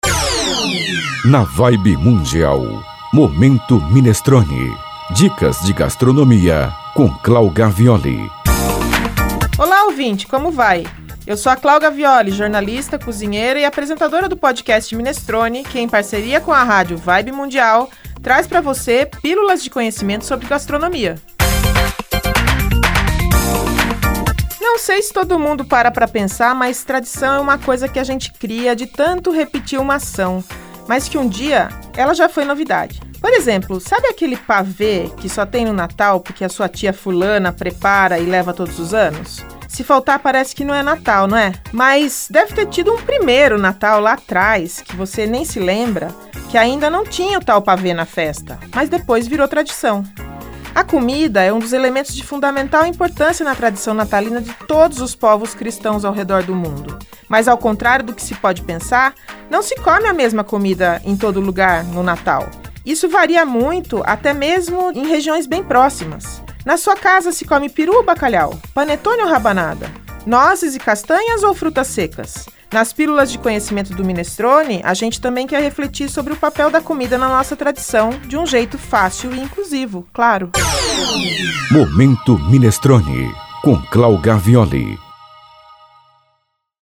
Três vezes ao dia, durante a programação da rádio (às 7h25, 14h25 e 22h25), são veiculadas pílulas de conhecimento em gastronomia.